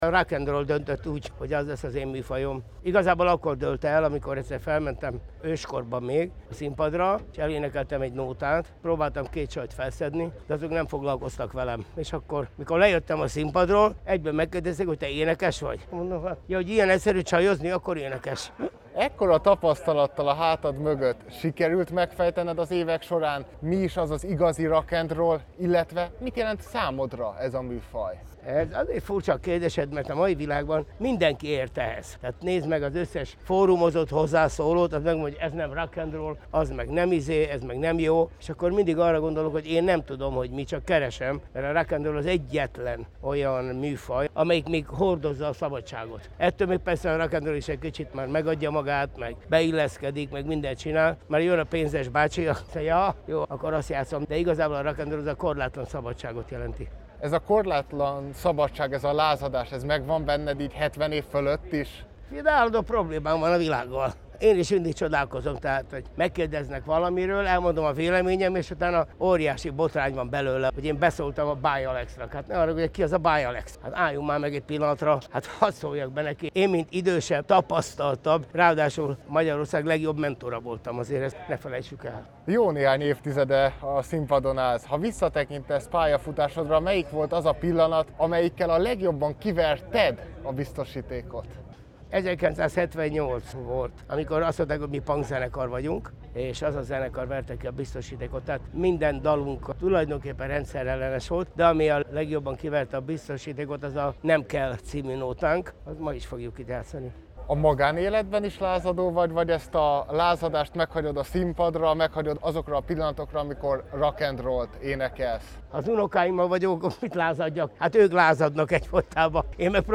A Vásárhelyi Forgatagon